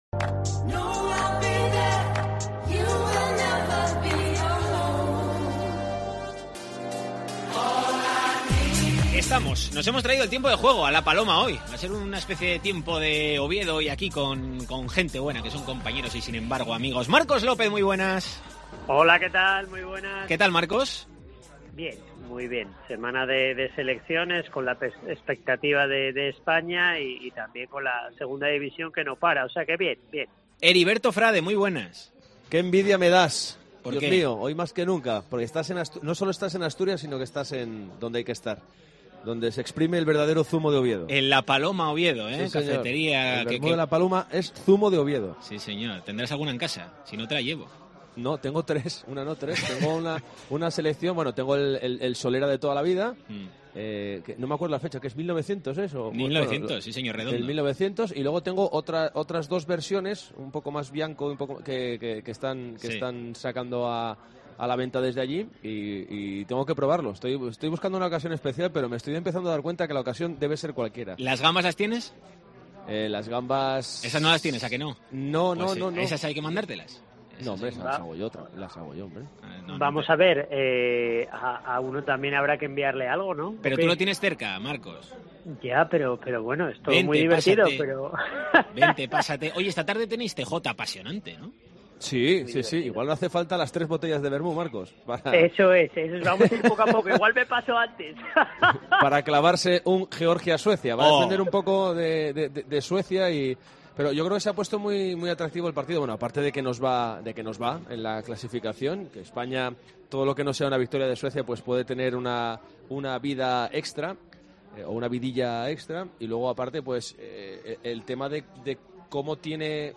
La tertulia del Real Oviedo en DCA
Desde La Paloma